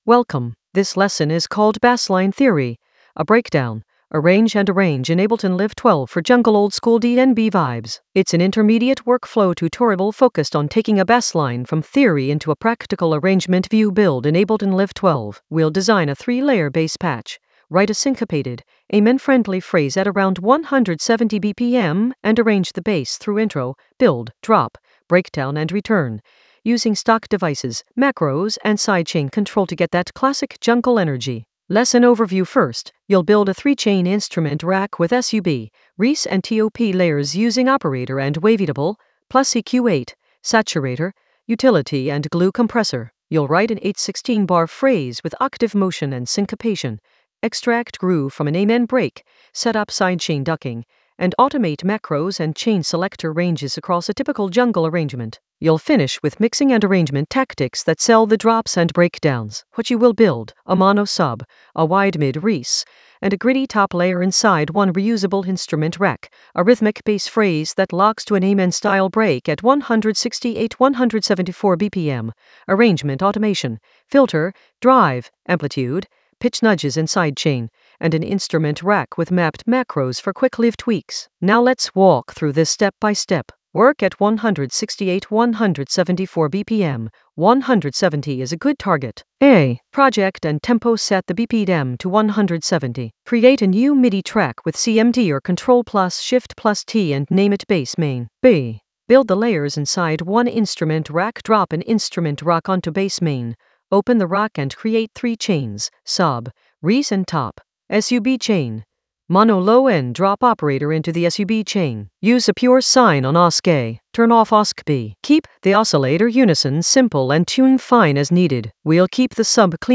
An AI-generated intermediate Ableton lesson focused on Bassline Theory a breakdown: arrange and arrange in Ableton Live 12 for jungle oldskool DnB vibes in the Workflow area of drum and bass production.
Narrated lesson audio
The voice track includes the tutorial plus extra teacher commentary.